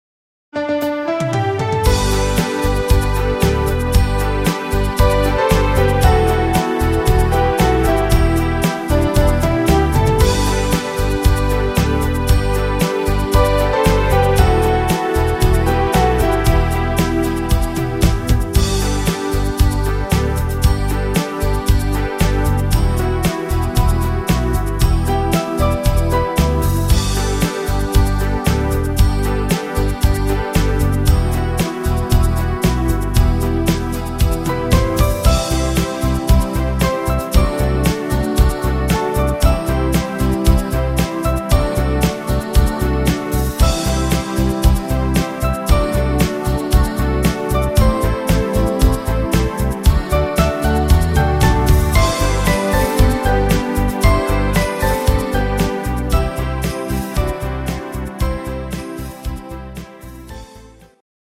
Rhythmus  Rhumba
Art  Deutsch, Weibliche Interpreten, Weihnachtslieder